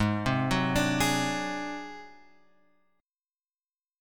G# 7th Flat 5th